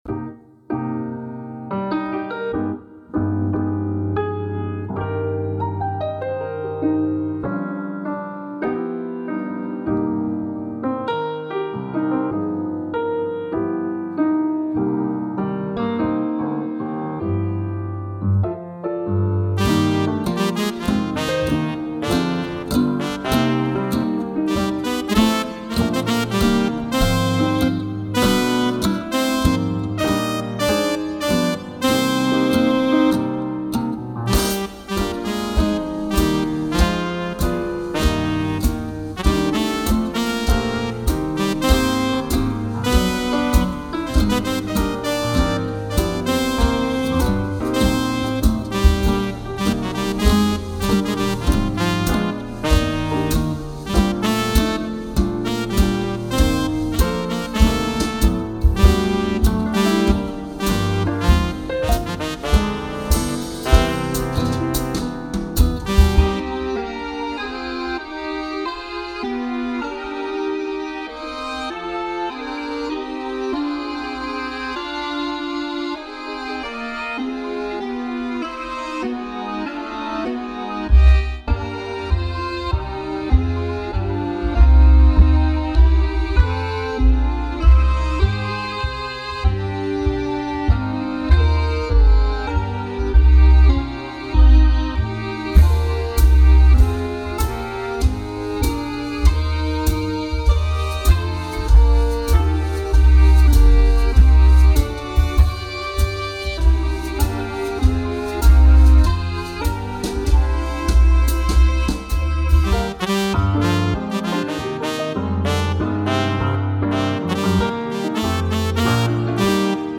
versions instrumentales